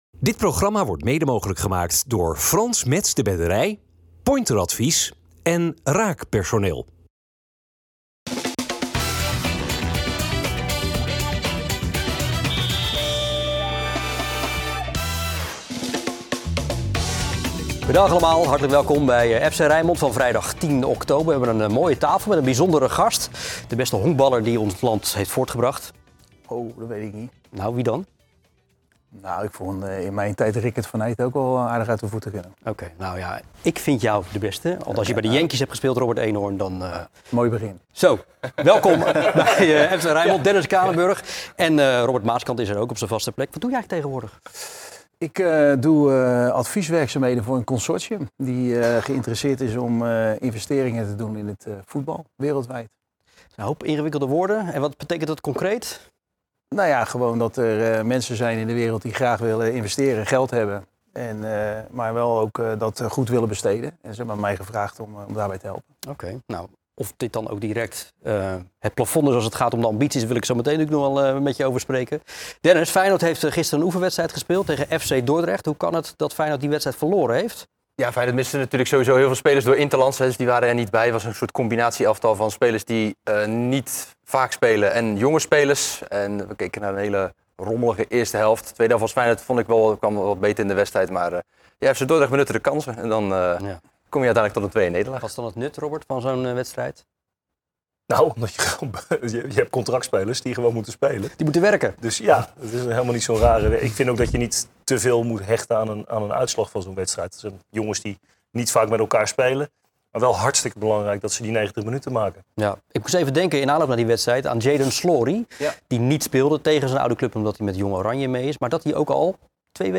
In onze voetbaltalkshow FC Rijnmond